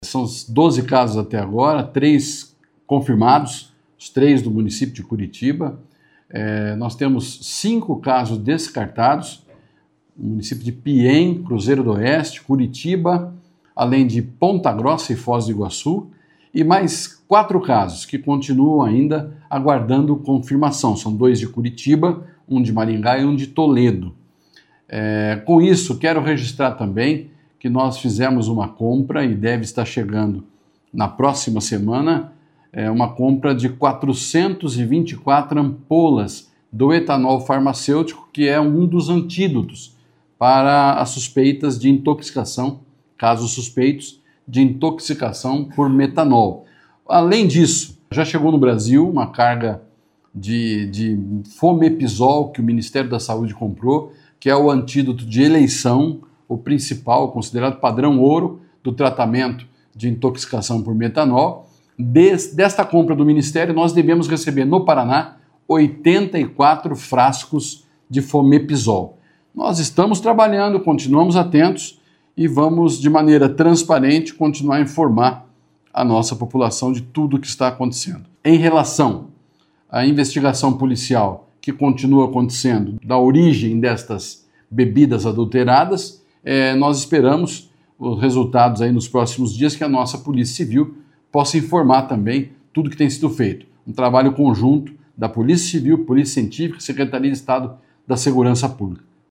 Sonora do secretário da Saúde, Beto Preto, sobre as atualizações dos casos de intoxicação por metanol no Estado